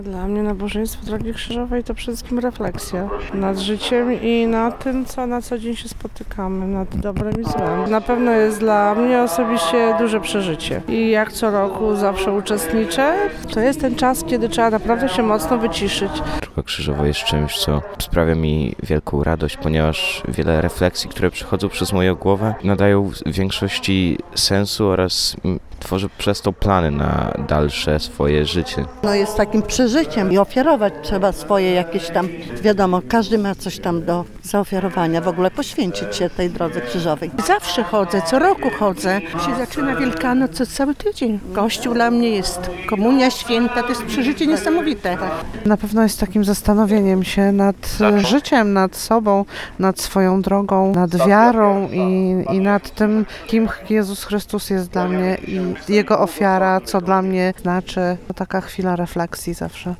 – To okazja do modlitwy, zadumy nad życiem i dobry sposób na przygotowanie do Świąt Wielkanocnych – mówili uczestnicy Drogi Krzyżowej.
droga-krzyżowa-sonda-1.mp3